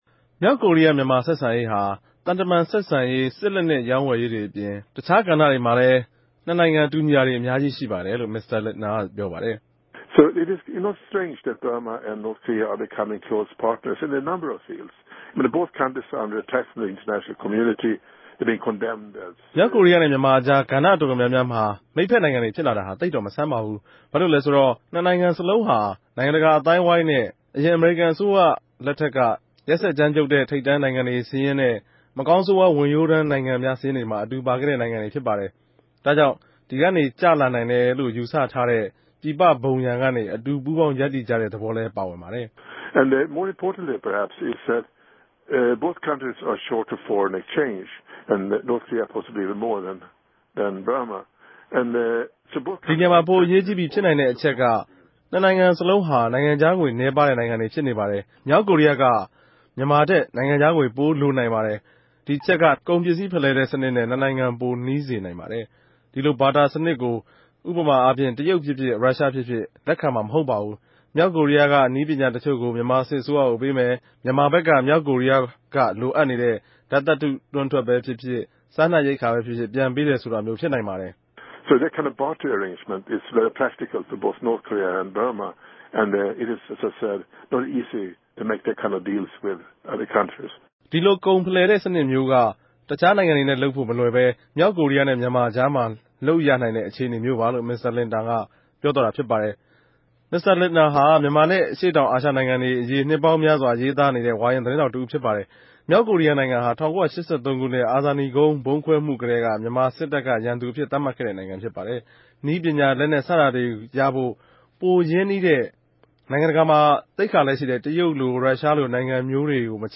ဆက်သြယ်မေးူမန်းခဵက် အပိုင်း (၂) ။